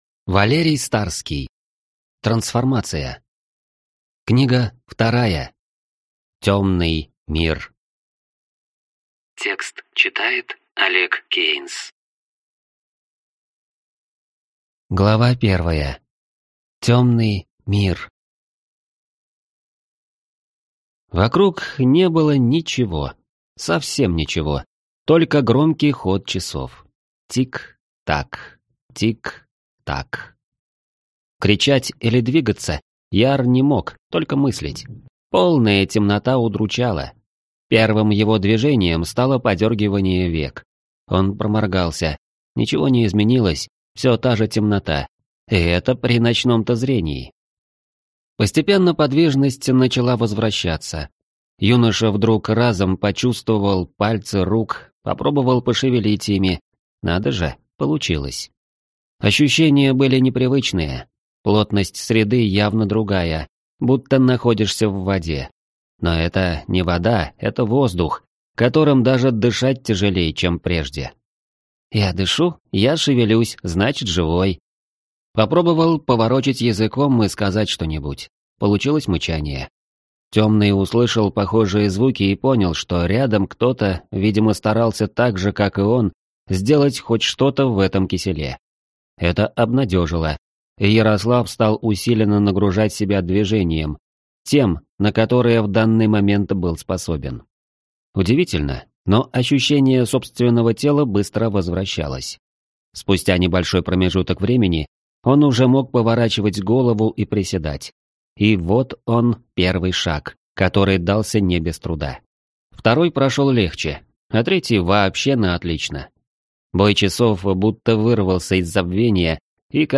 Аудиокнига Темный Мир | Библиотека аудиокниг
Прослушать и бесплатно скачать фрагмент аудиокниги